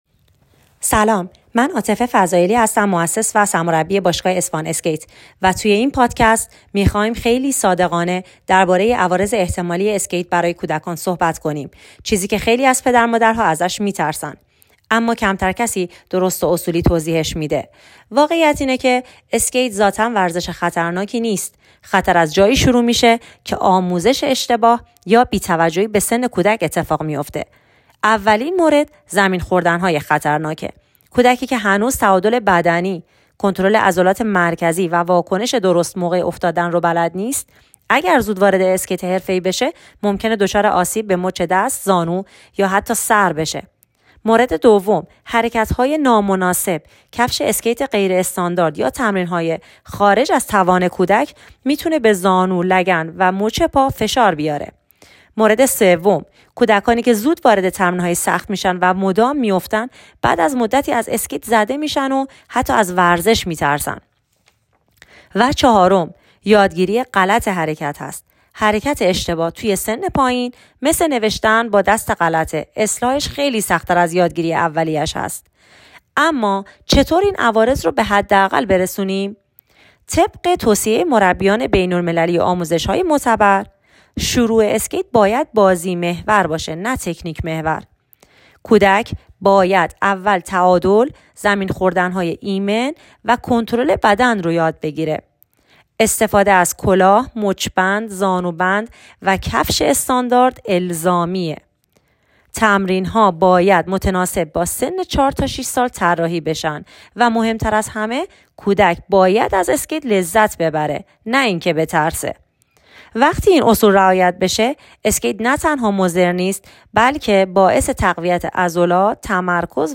خالی از لطف نیست پیش از ادامه این مقاله به نظر کارشناس و مربی اسکیت، درباره‌ی عوارض احتمالی اسکیت برای کودکان گوش فرا دهید: